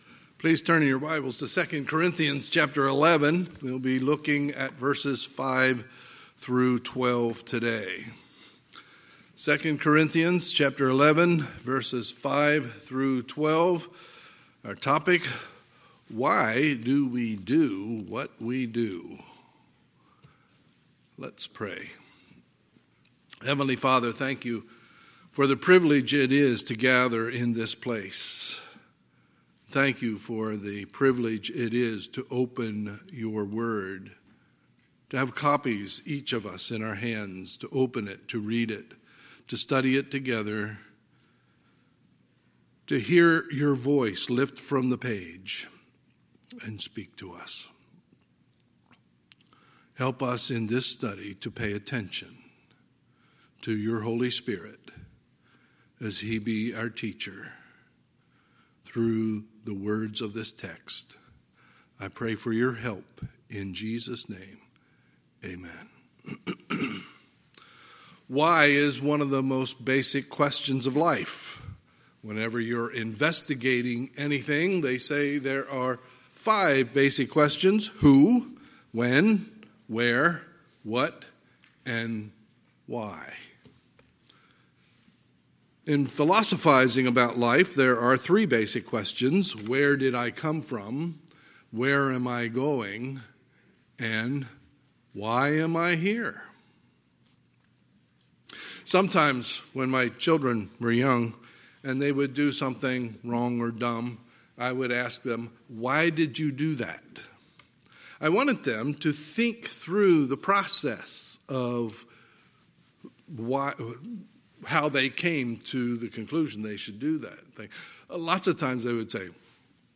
Sunday, October 18, 2020 – Sunday Morning Service